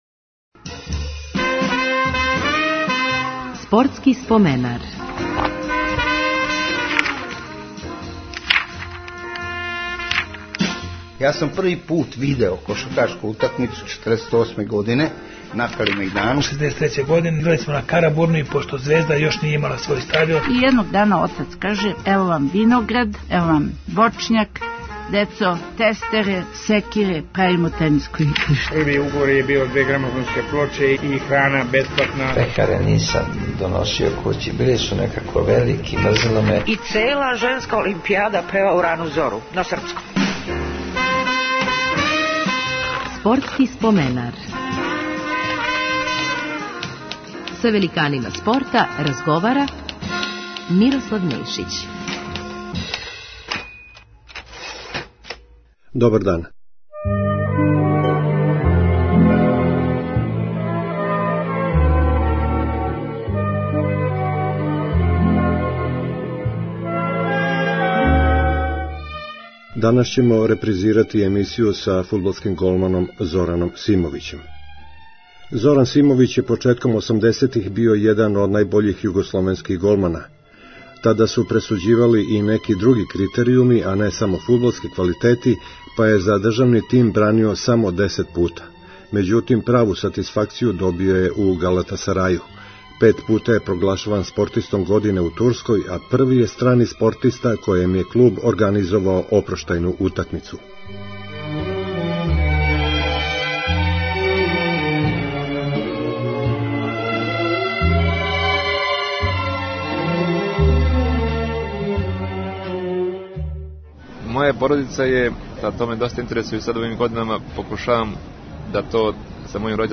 Биће коришћени и архивски материјали из меча Југославија-Бугарска уз чувене коментаре Младена Делића.